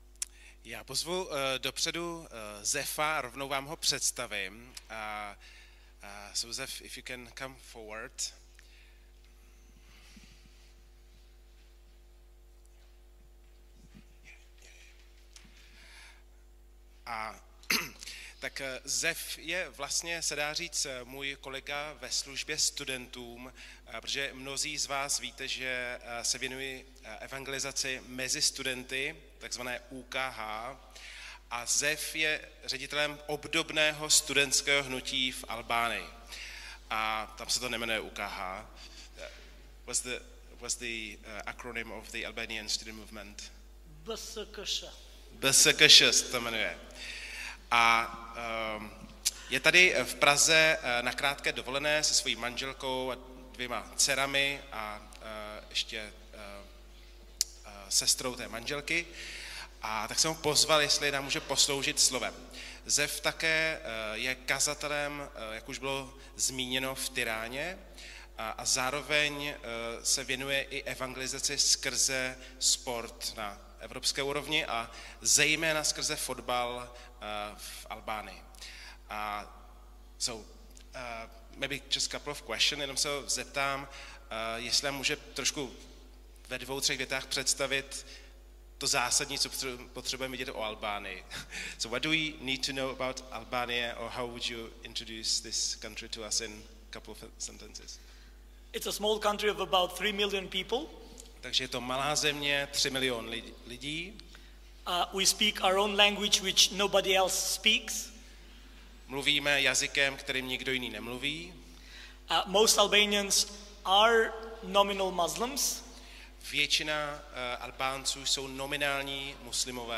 Nedělení kázání – 15.5.2022 Radost v epištole Filipským